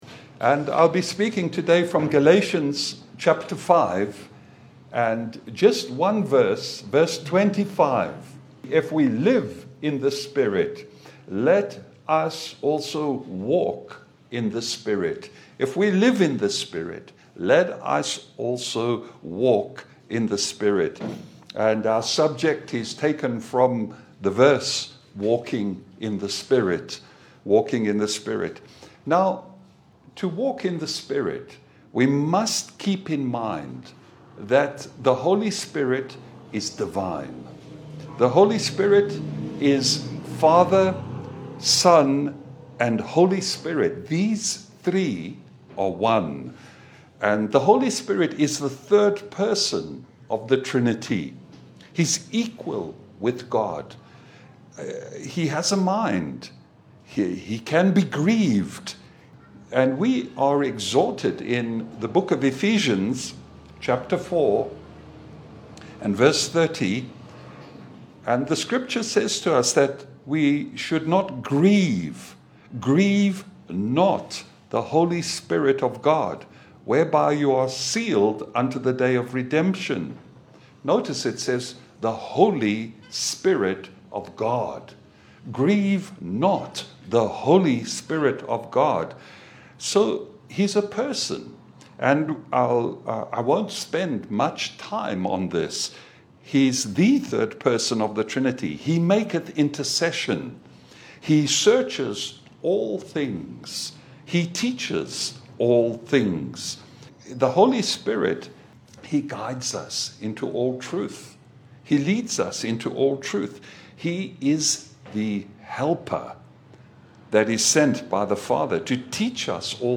A clear biblical sermon on walking in the Spirit, true holiness, and living the Christian life through God’s power, not self-effort.
Service Type: Sunday Bible fellowship